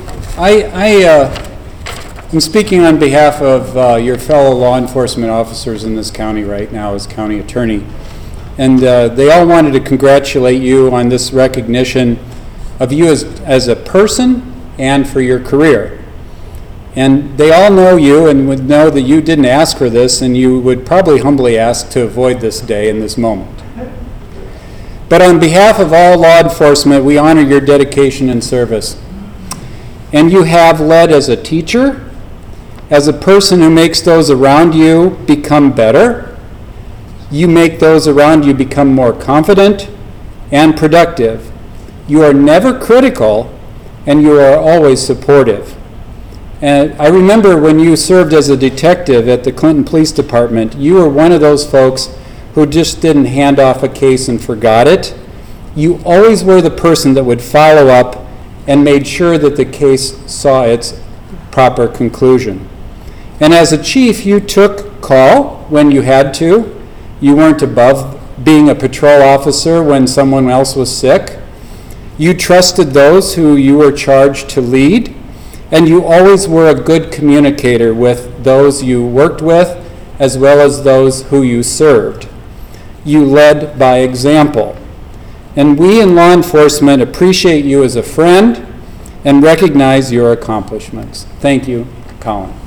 Clinton County Attorney Mike Wolfe praised Reid’s work.